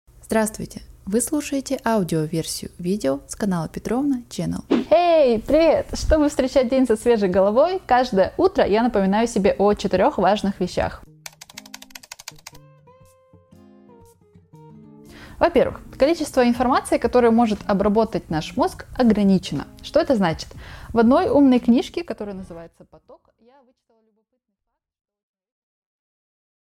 Аудиокнига 4 важных правила осознанной жизни | Библиотека аудиокниг